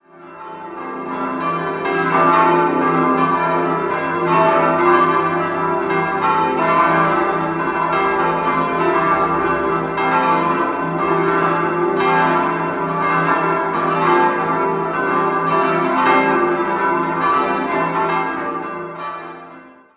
7-stimmiges Geläut: d'-e'-g'-a'-c''-d''-e'' Die große Glocke stammt von Johann Florido (Straubing) aus dem Jahr 1757/58.